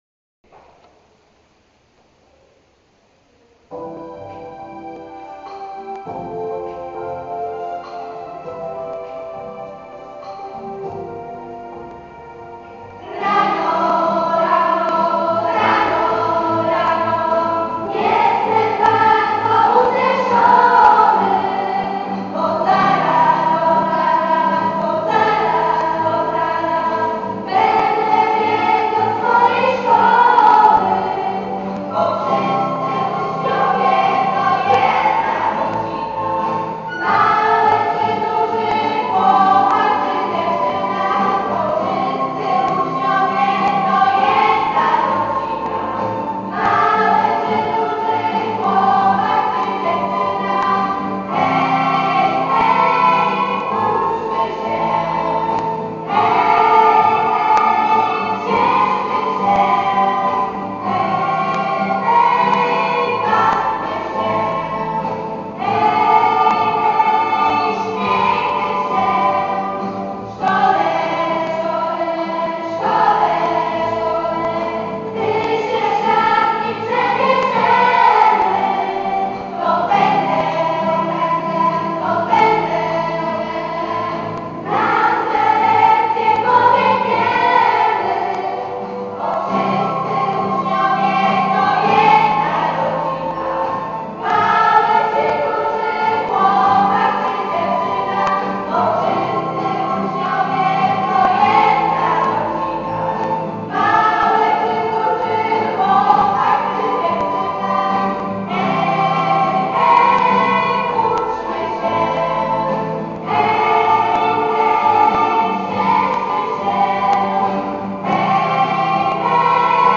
Hymn szkolny